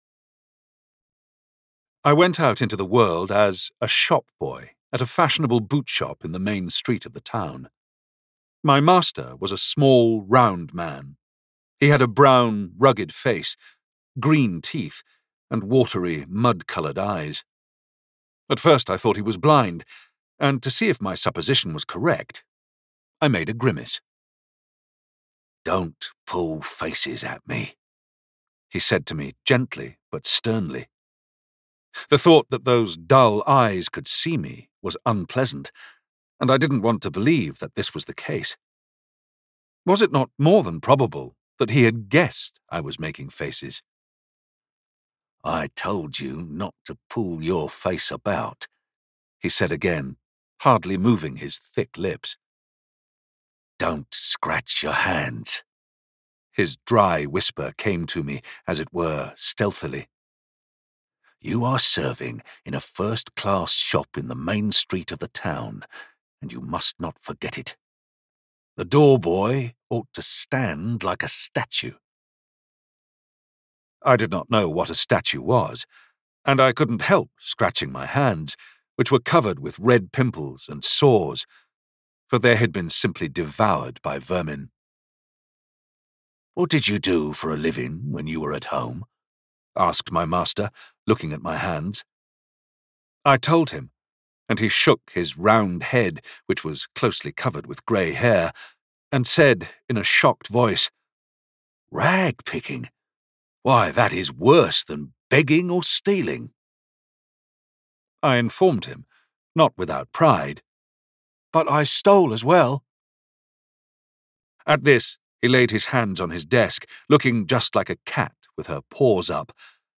Audiobook In the World Maxim Gorky.
Ukázka z knihy